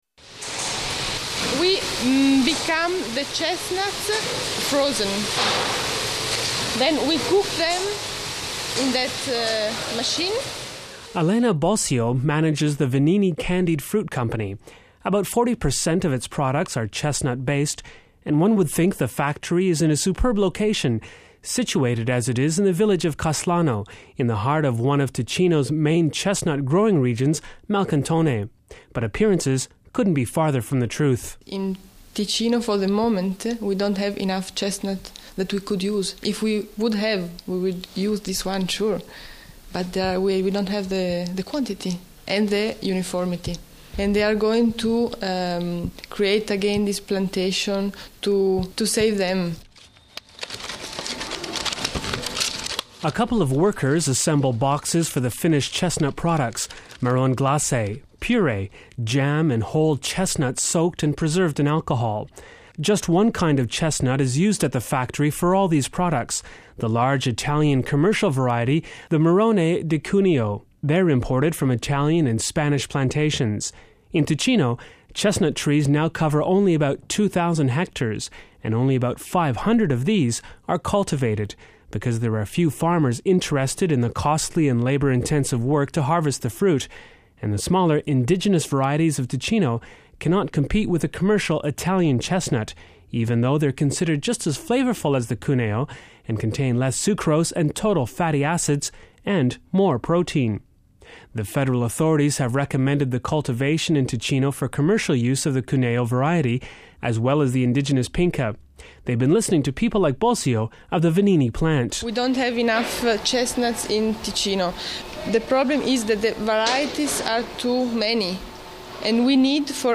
reports on the state of the Swiss chestnut industry.